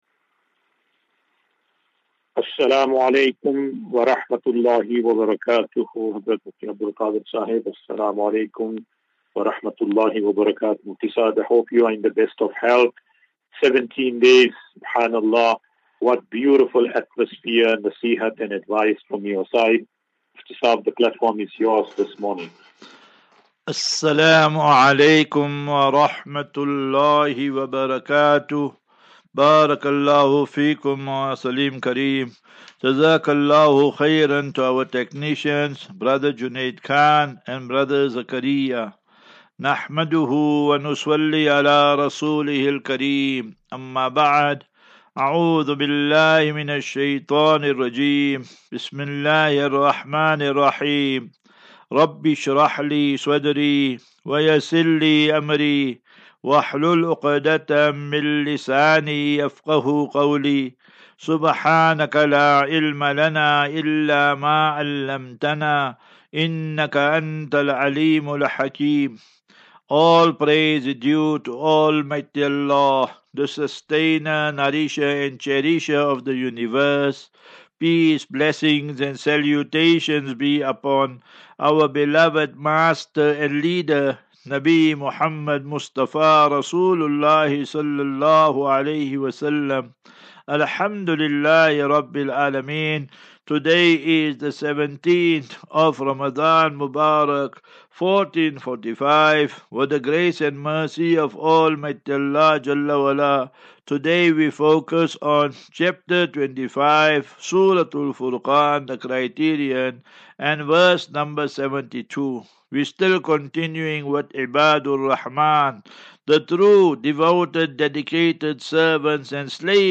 Daily Naseeha.
As Safinatu Ilal Jannah Naseeha and Q and A 28 Mar 28 March 2024.